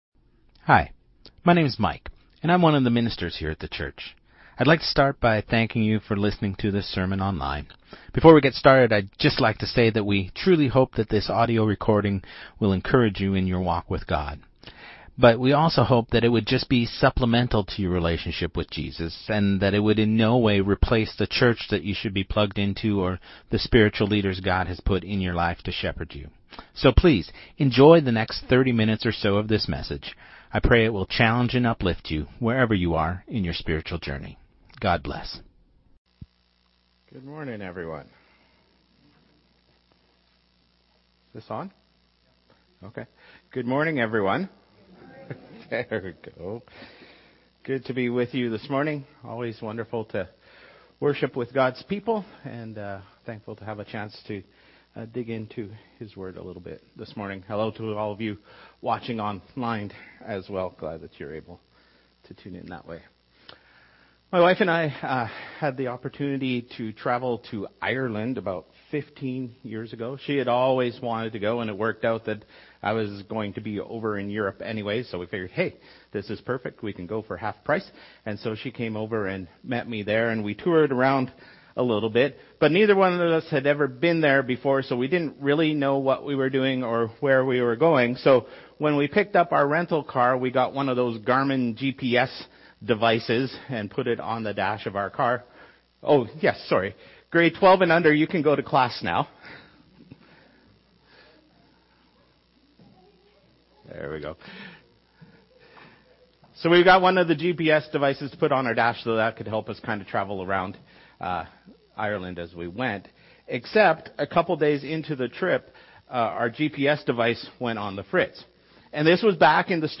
Sermon2026-02-01